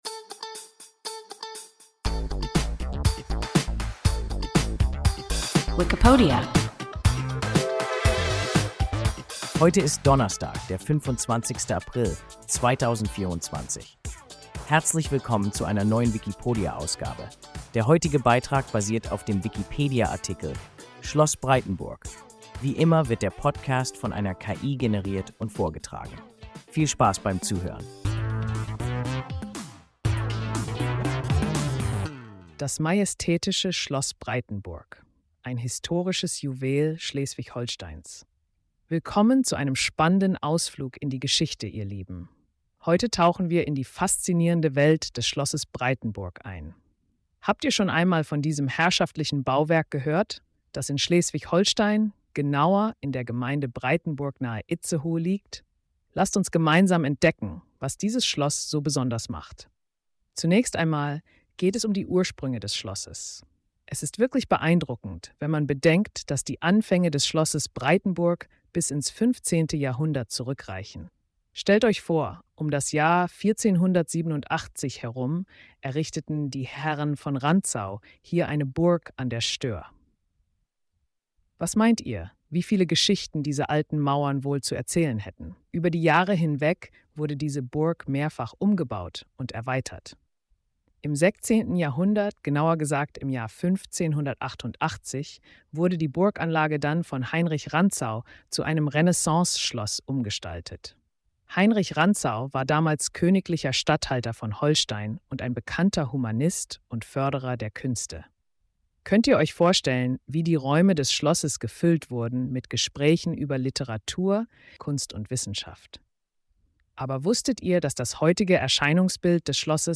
Schloss Breitenburg – WIKIPODIA – ein KI Podcast